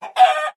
sounds / mob / chicken / hurt1.mp3
hurt1.mp3